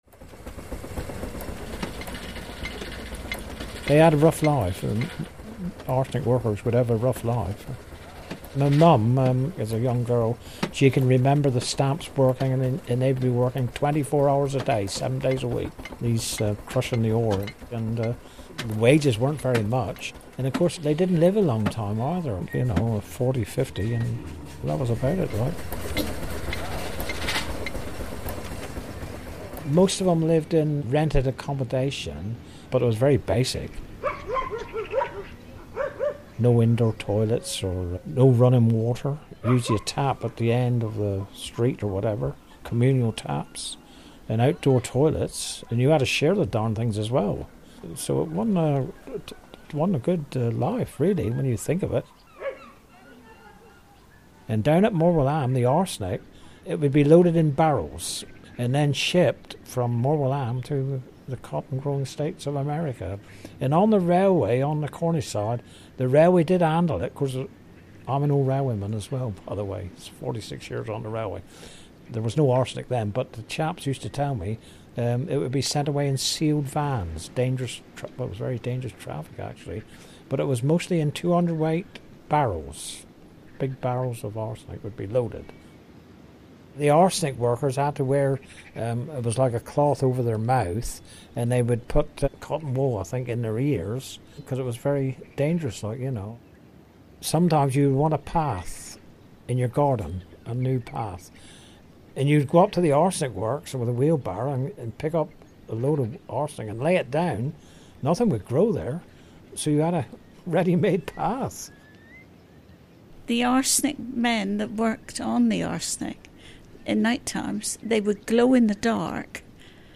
Listen to locals share memories from the past.